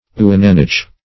Search Result for " ouananiche" : The Collaborative International Dictionary of English v.0.48: Ouananiche \Oua`na`niche"\, n. [Canadian F., of Amer.
ouananiche.mp3